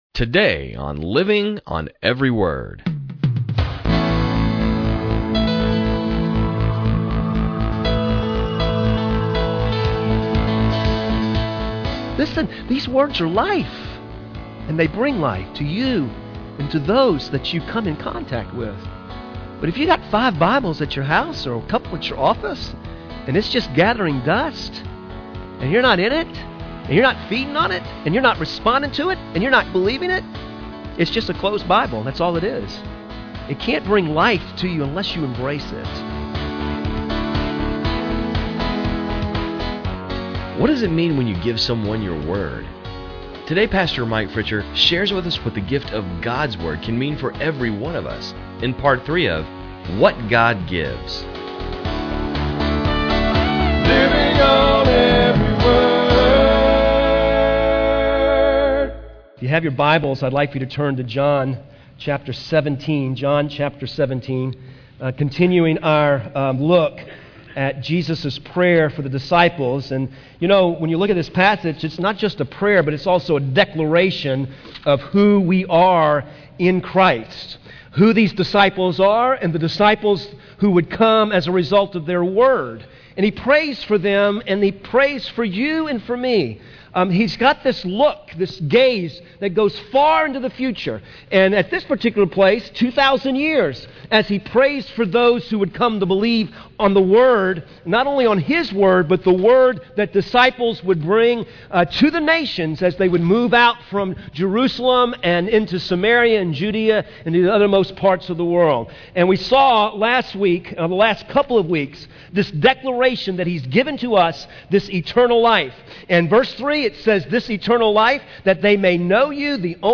sermons on cd